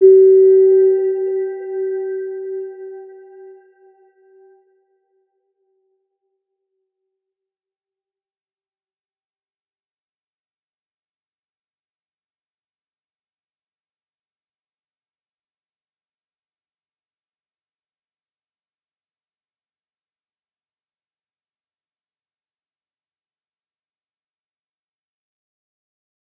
Round-Bell-G4-f.wav